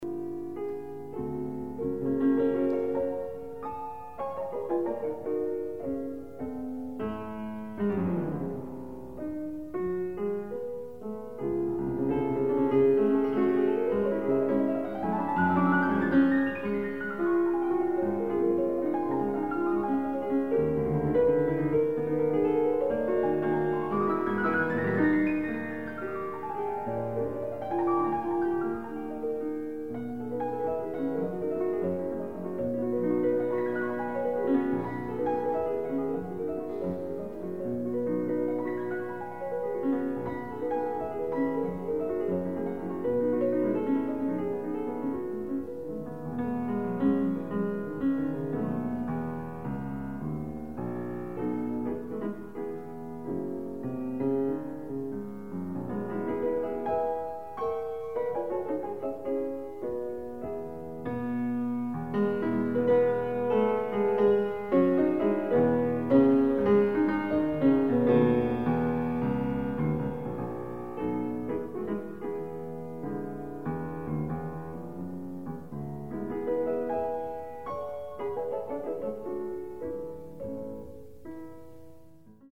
Genreperformed music
Additional Date(s)Recorded September 12, 1977 in the Ed Landreth Hall, Texas Christian University, Fort Worth, Texas
Ballades (Instrumental music)
Short audio samples from performance